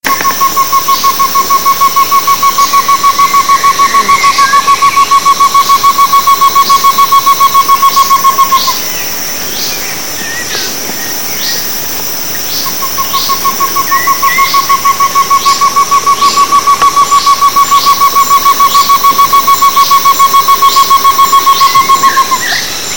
Tovaca Común (Chamaeza campanisona)
Nombre en inglés: Short-tailed Antthrush
Fase de la vida: Adulto
Localidad o área protegida: Reserva Privada y Ecolodge Surucuá
Condición: Silvestre
Certeza: Vocalización Grabada